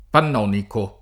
pann0niko] (pl. m. -ci): la ceramica pannonica; la pianura pannonica; la vegetazione pannonica